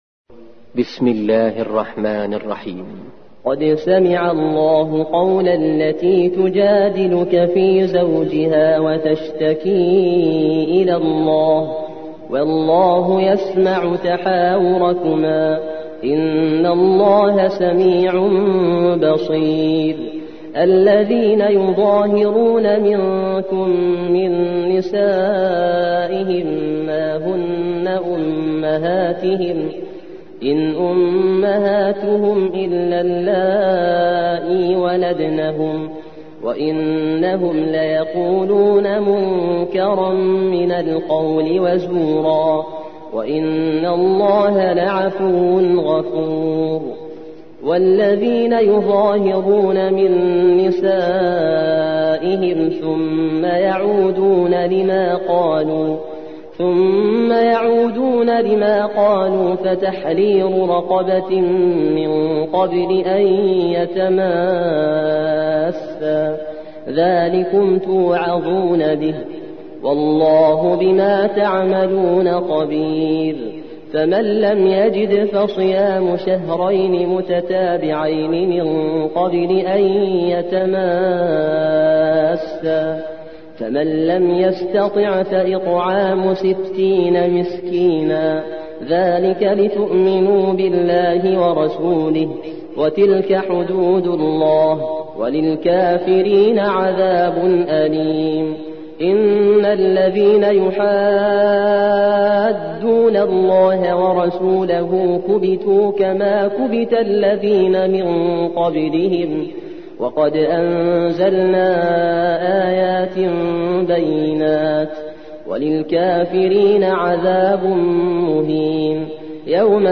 58. سورة المجادلة / القارئ